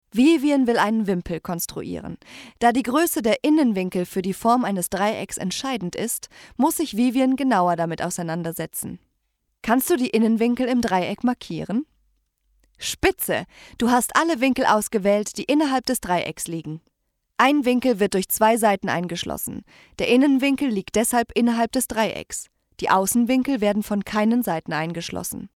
Junge Stimme mit viel Theatererfahrung, professionelle Schauspielerin
Sprechprobe: Industrie (Muttersprache):